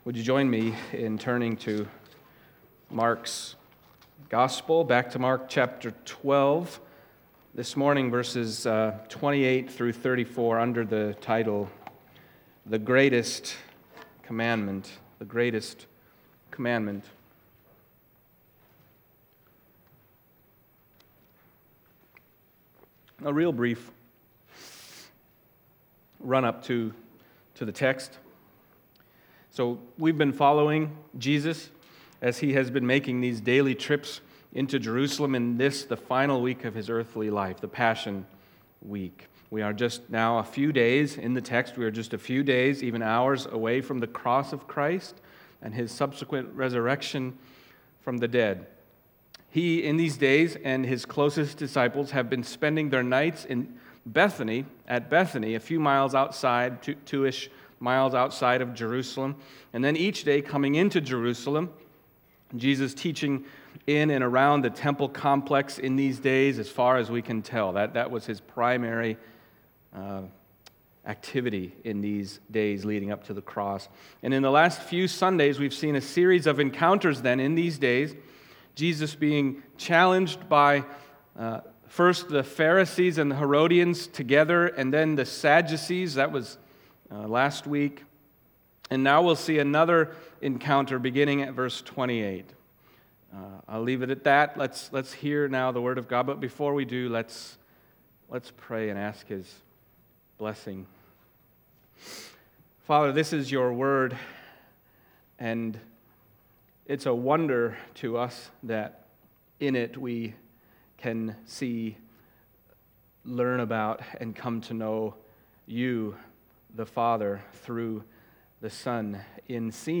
Mark Passage: Mark 12:28-34 Service Type: Sunday Morning Mark 12:28-34 « Is There Marriage in Heaven?